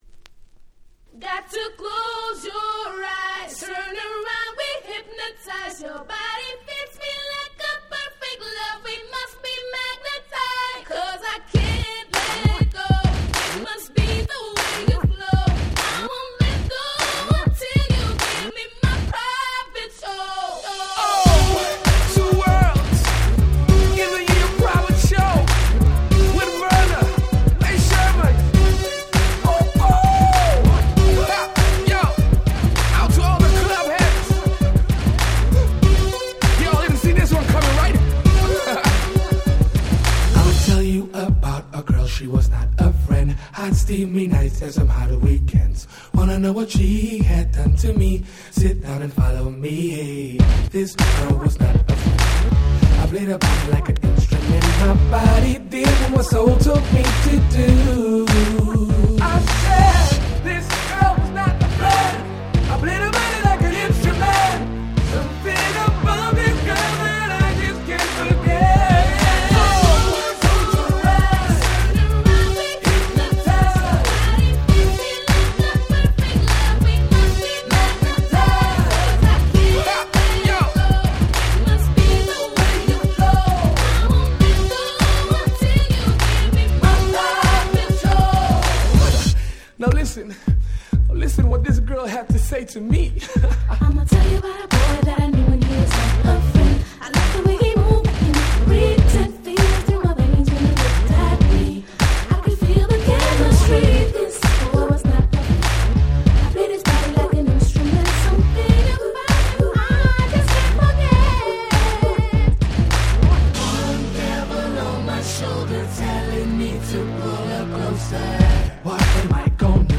ダンスホールレゲエ レゲトン